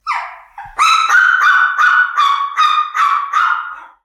소형 개1(실내)
small_dog1.mp3